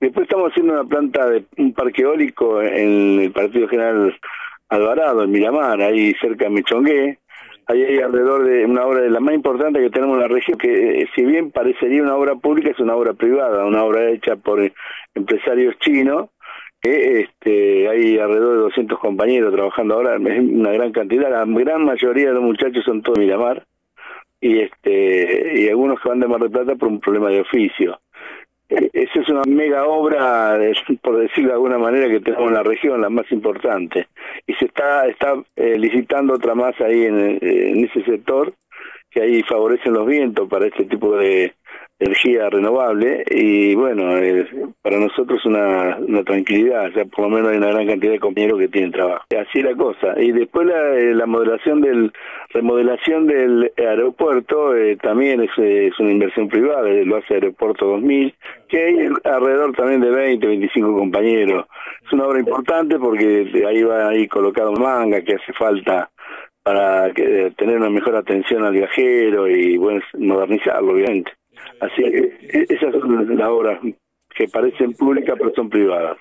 mostró su preocupación por la situación de la actividad en una entrevista en el  programa radial “Bien Despiertos”, emitido de lunes a viernes de 7 a 9 por De la Azotea 88.7.